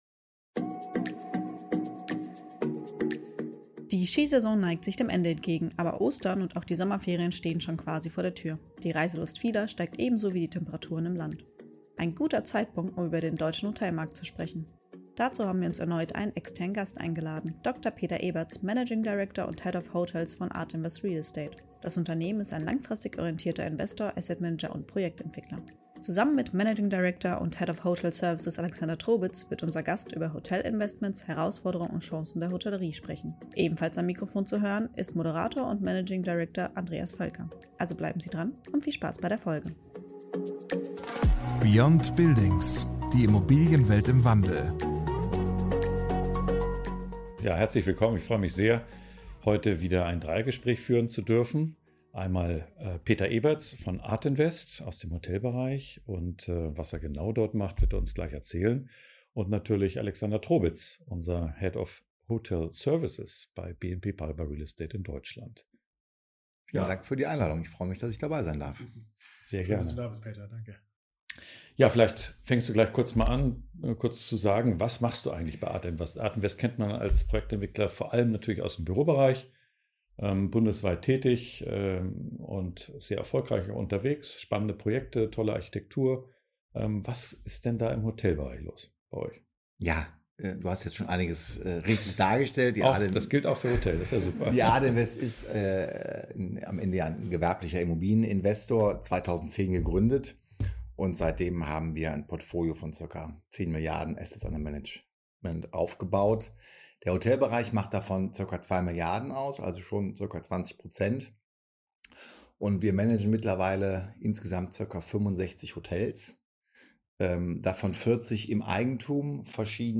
Ein guter Zeitpunkt, um über den deutschen Hotelmarkt zu sprechen. Dazu haben wir uns erneut einen externen Gast eingeladen